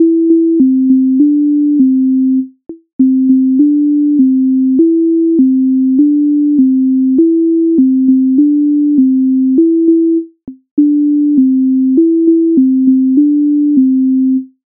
Українська народна пісня